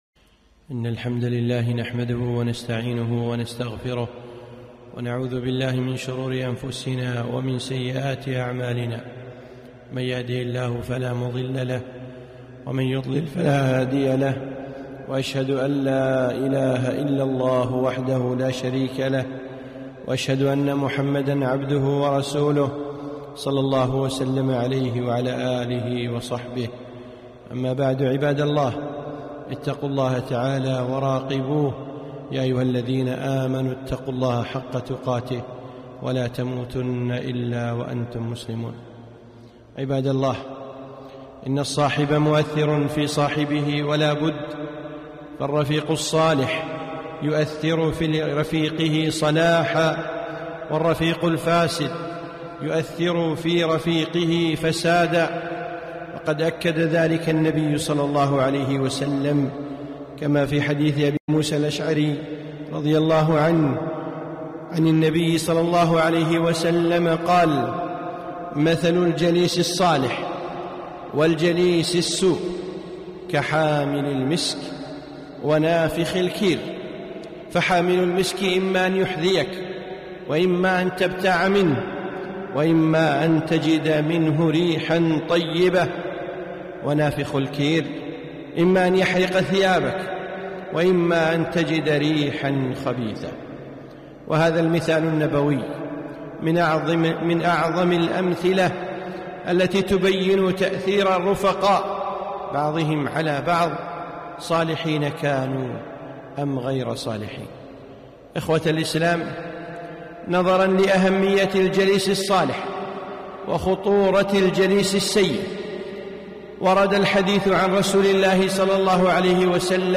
خطبة - الحذر من جلساء السوء